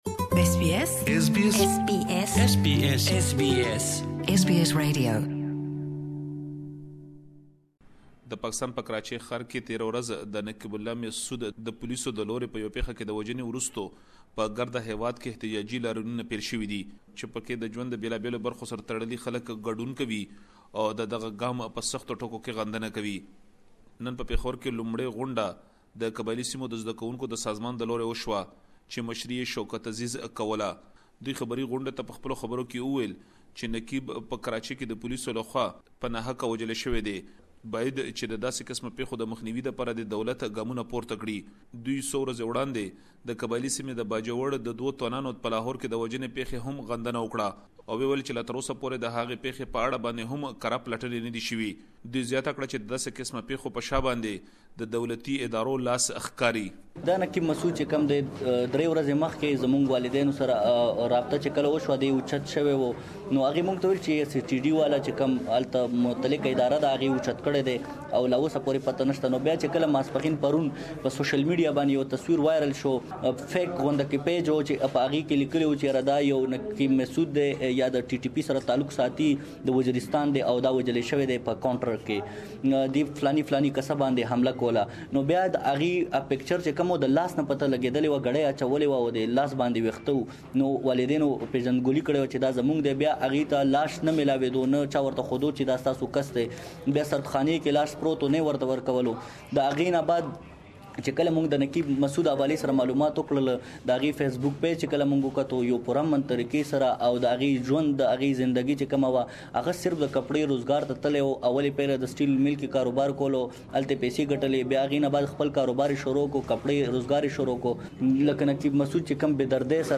SBS Pashto